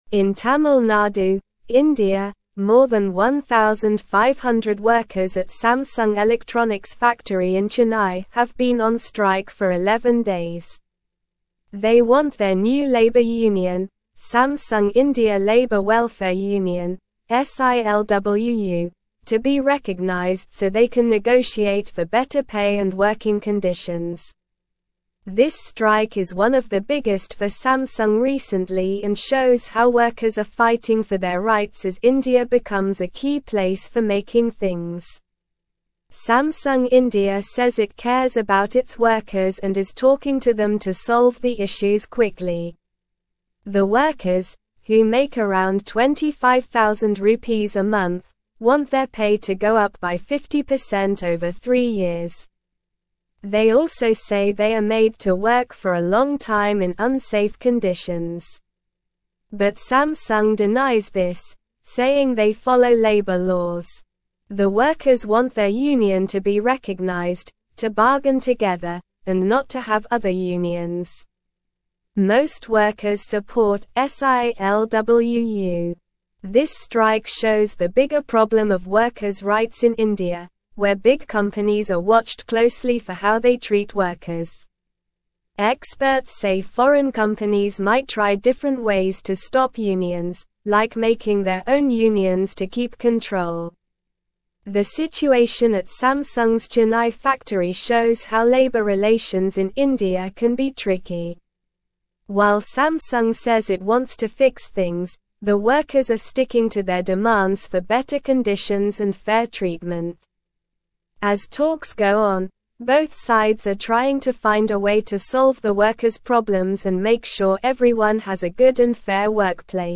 Slow
English-Newsroom-Upper-Intermediate-SLOW-Reading-Samsung-Workers-in-India-Strike-for-Union-Recognition.mp3